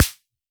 RDM_Copicat_SR88-Snr.wav